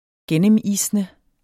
Udtale [ ˈgεnəmˌisnə ]